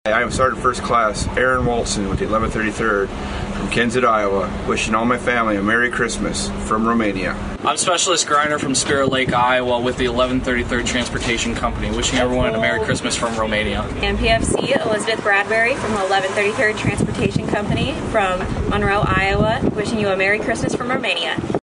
(Undated)  —  Iowa National Guard Soldiers deployed overseas are offering their holiday greetings.